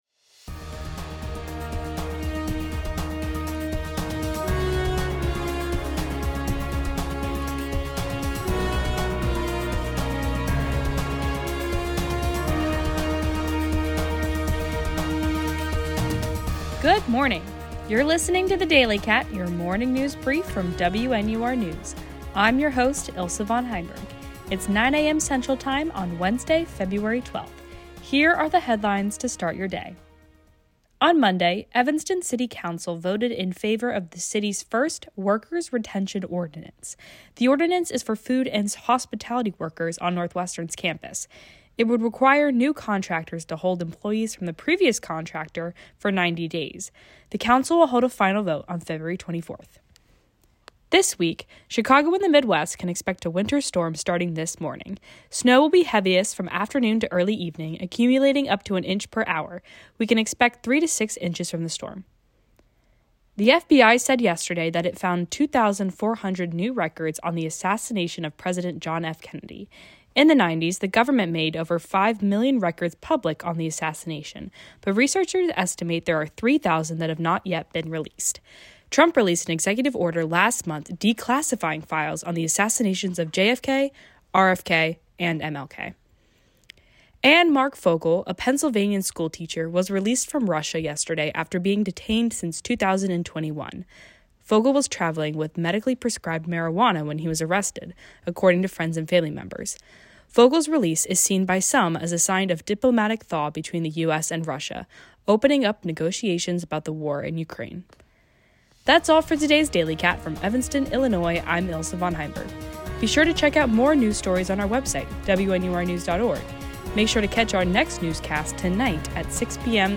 February 12, 2025: Evanston Worker’s Retention Ordinance, JFK files, Chicago winter storm, Marc Fogel, Russia relations. WNUR News broadcasts live at 6 pm CST on Mondays, Wednesdays, and Fridays on WNUR 89.3 FM.